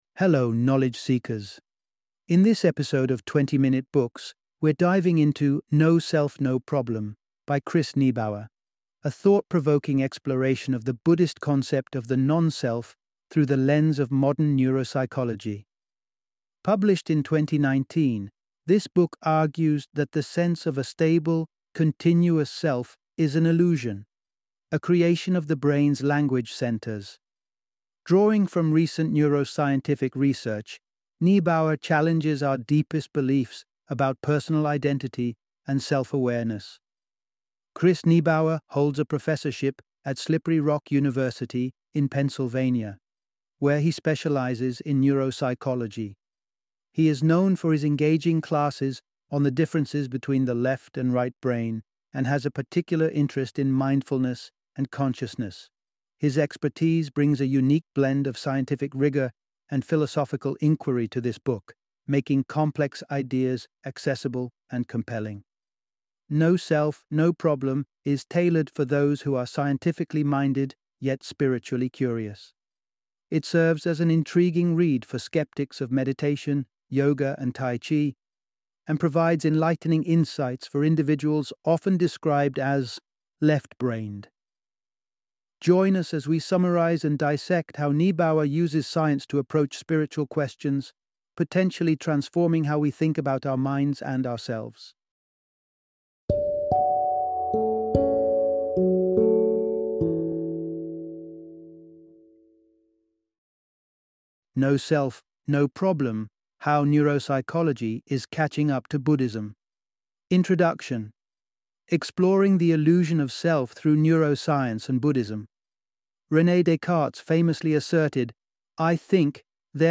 No Self, No Problem - Audiobook Summary